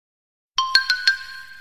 ring.i6Iec9Ue.mp3